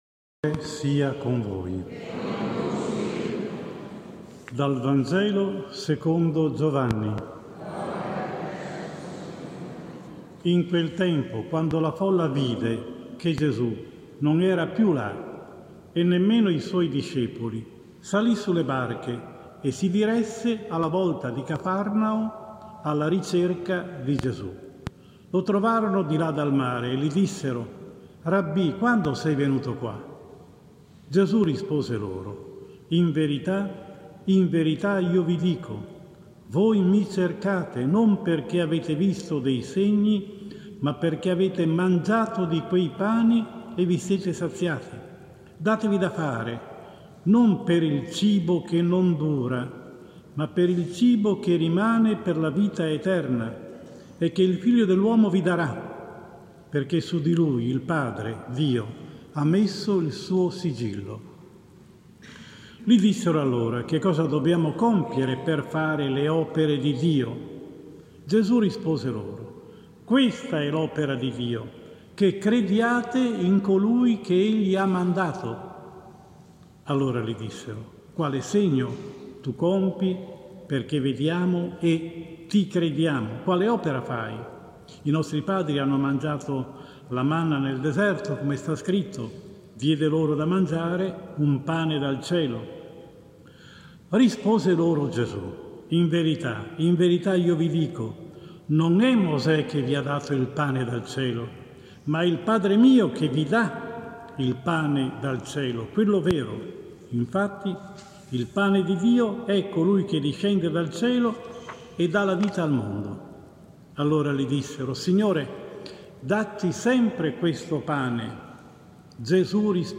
1 Agosto 2021, XVIII Domenica, tempo ordinario, anno B – omelia